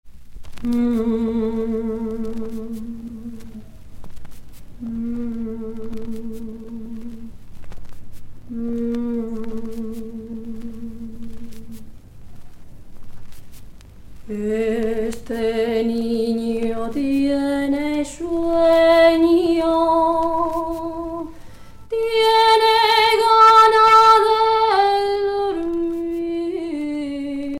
enfantine : berceuse